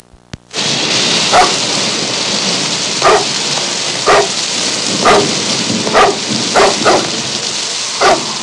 Dog In Thunderstorm Sound Effect
Download a high-quality dog in thunderstorm sound effect.
dog-in-thunderstorm.mp3